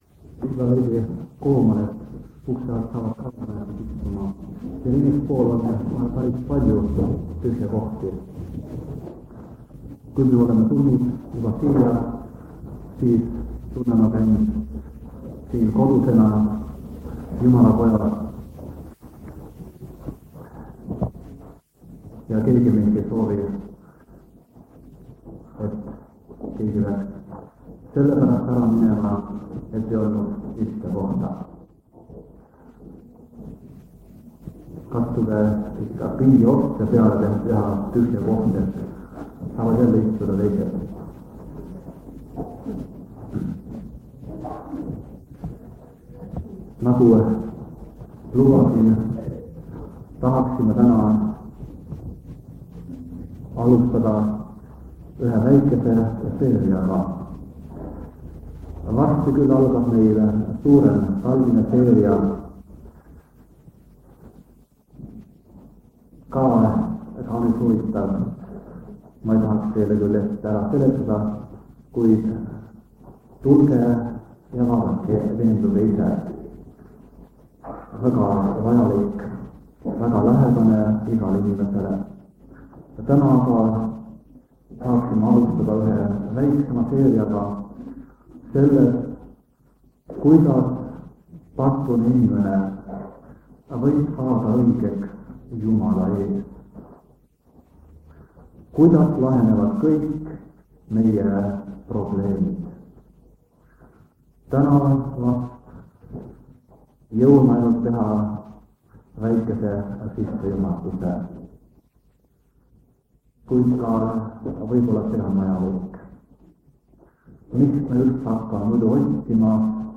Jutlused
Jutluste miniseeria vanadelt lintmaki lintidelt 1974 aasta sügisel.